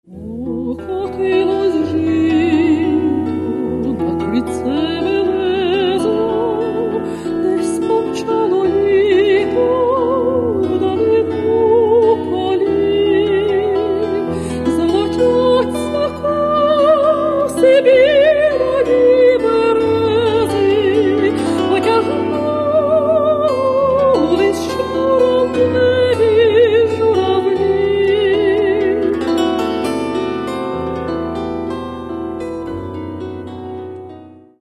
Каталог -> Народна -> Бандура, кобза тощо
Відома бандуристка